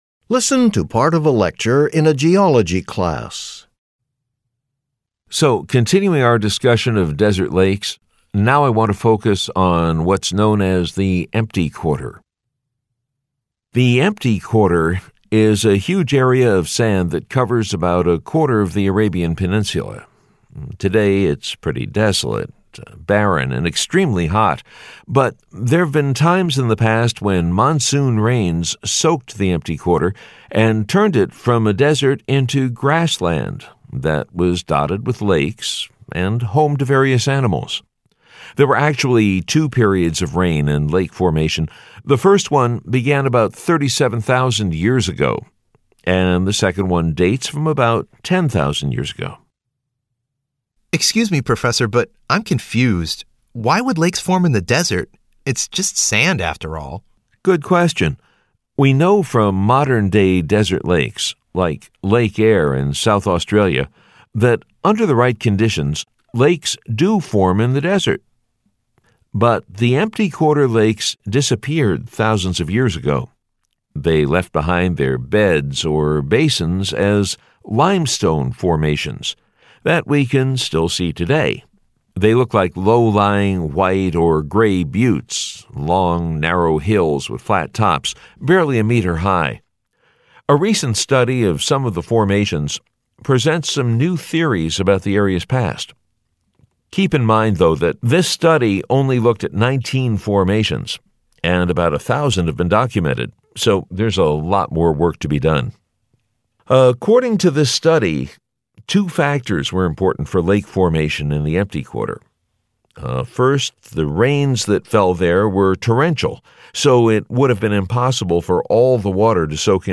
Directions: This section measures your ability to understand conversations and lectures in English.